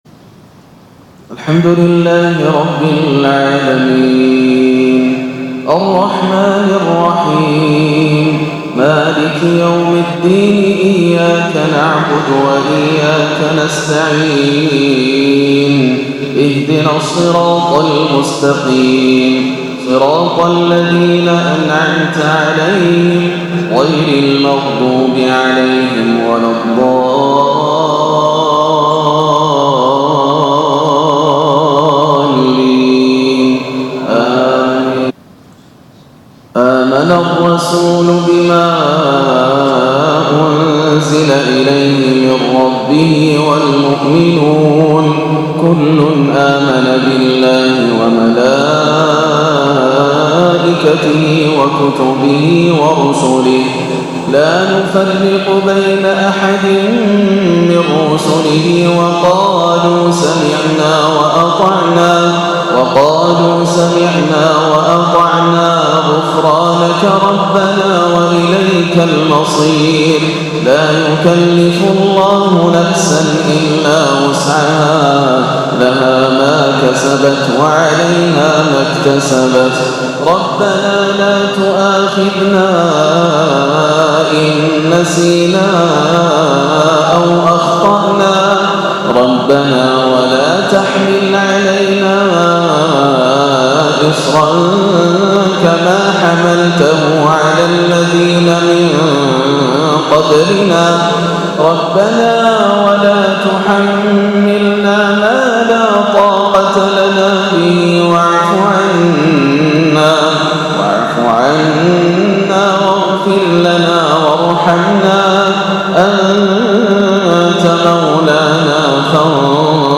صلاة المغرب 2-6-1439هـ خواتيم سورة البقرة 285-286 و الكافرون > عام 1439 > الفروض - تلاوات ياسر الدوسري